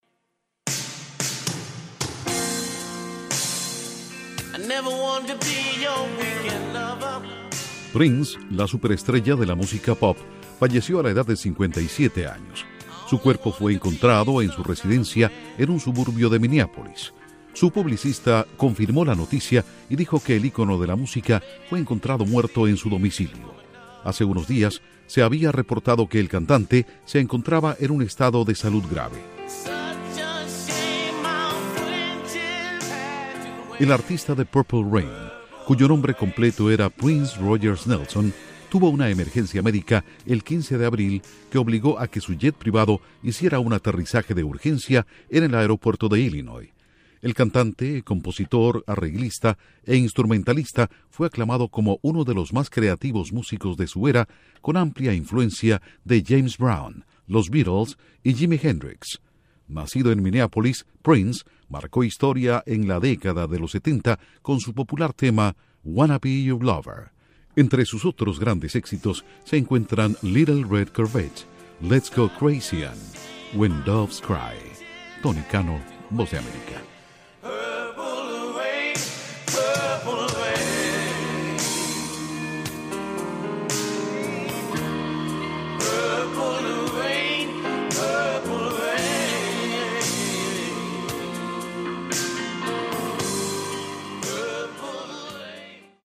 Contiene música al principio y al final.